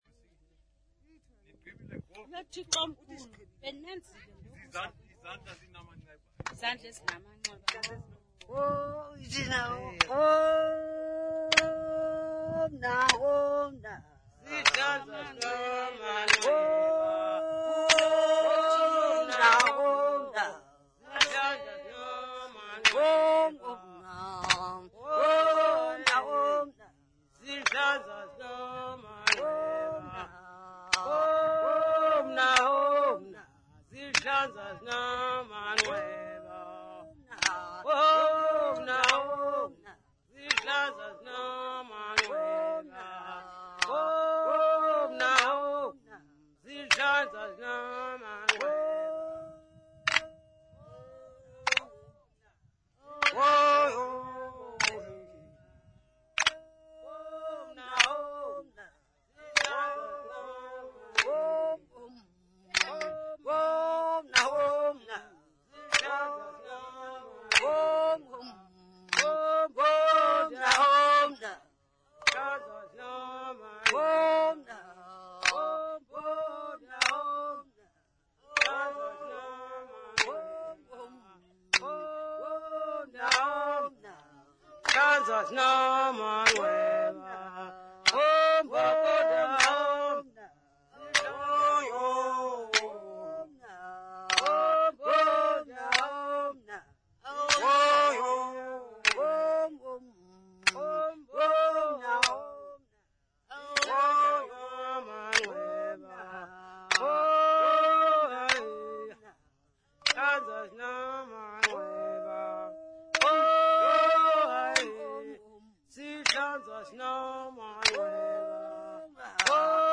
Xhosa women
Sacred music South Africa
Folk music South Africa
Stringed instrument music South Africa
Hand-clapping music South Africa
field recordings
Traditional Xhosa song with Uhadi and clapping accompaniment.